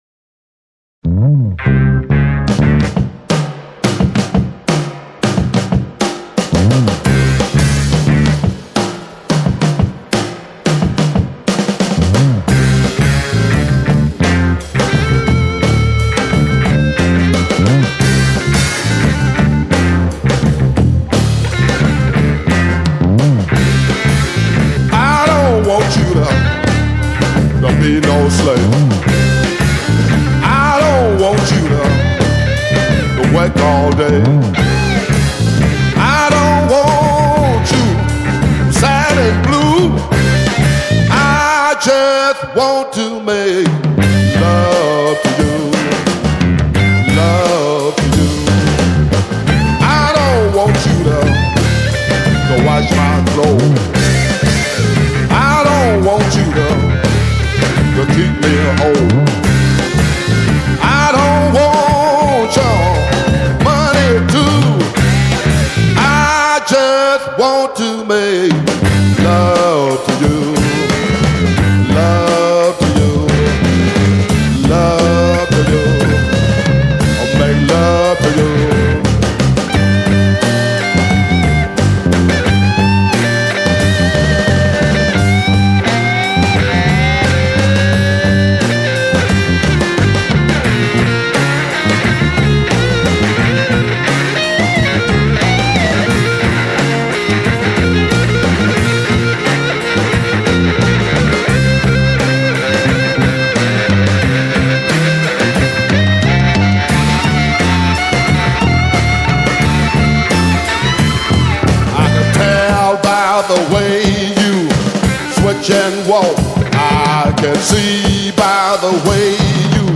Chicago Blues, Electric Blues, Delta Blues